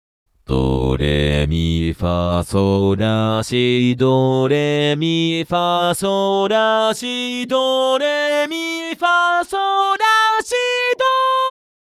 【説明】 ： 14reと比較したとき、落ち着きのある音源です
深みをもたせることができます